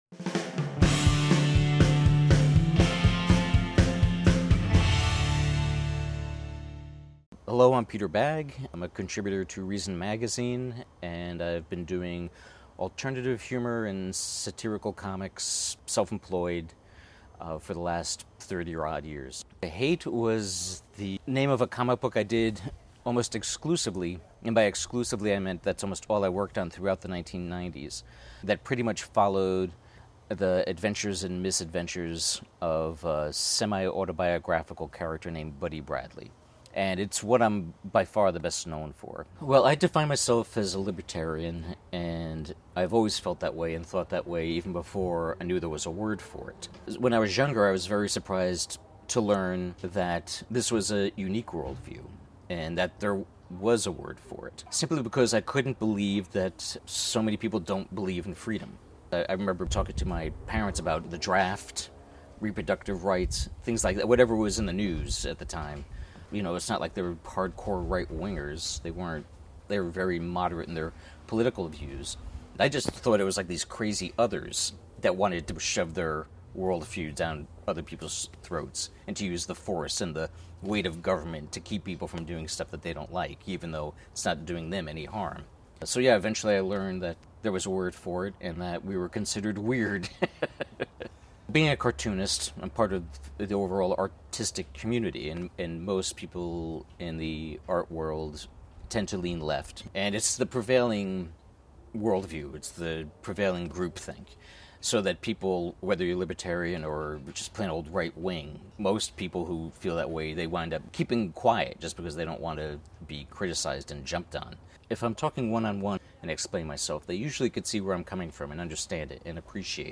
Interview with Reason Cartoonist Peter Bagge
Interview by Nick Gillespie.